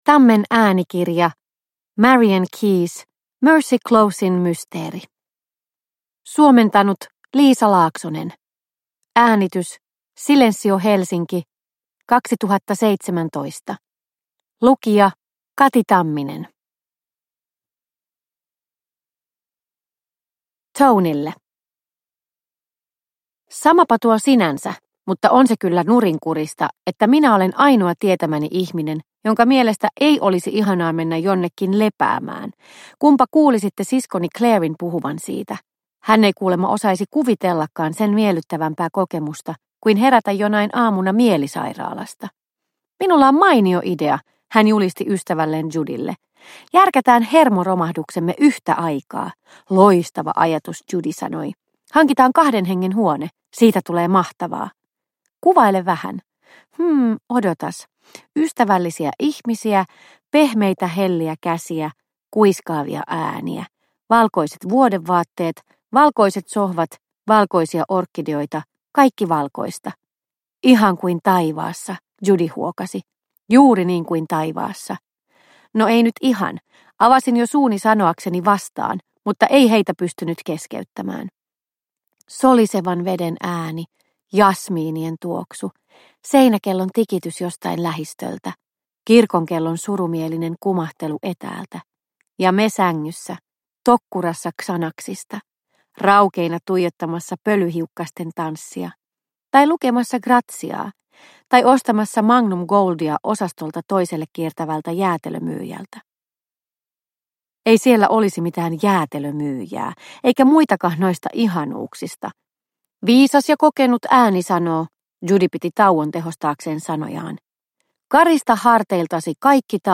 Mercy Closen mysteeri – Ljudbok – Laddas ner